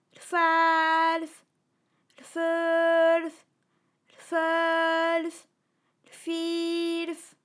ALYS-DB-001-FRA - First, previously private, UTAU French vocal library of ALYS